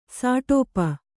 ♪ sāṭōpa